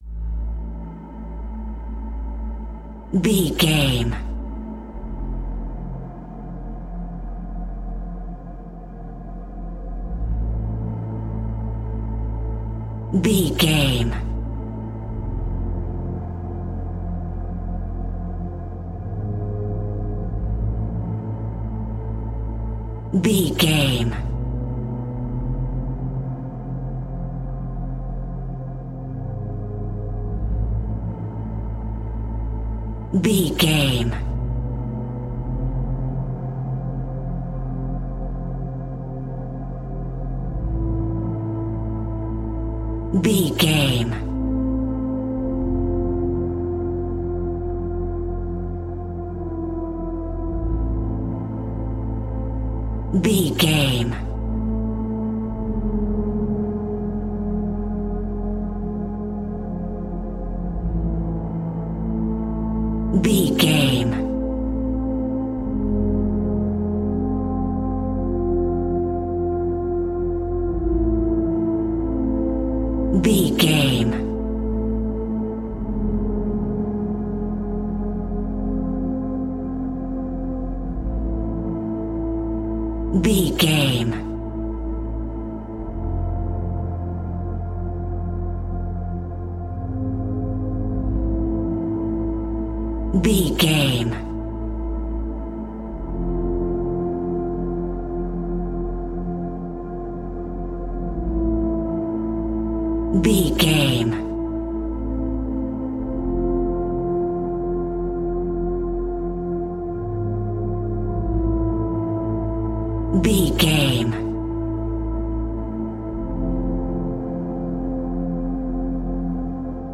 Modern Horror Film Music.
Aeolian/Minor
Slow
ominous
haunting
eerie
ethereal
synthesiser
Horror Pads
horror piano
Horror Synths